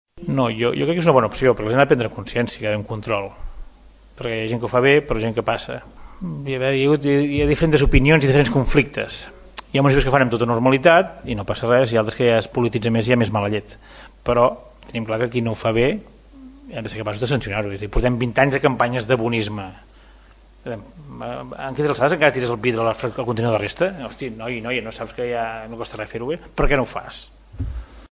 Josep Maria Tost, diu que veu bé que hi hagi municipis que obrin les bosses d’escombraries per comprovar si els seus habitants reciclen bé.